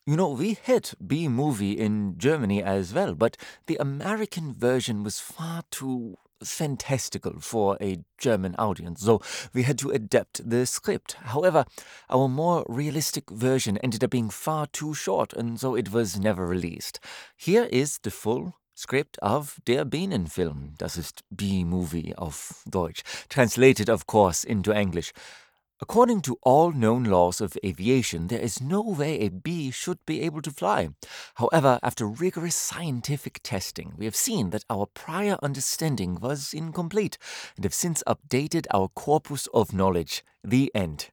german | natural
German.mp3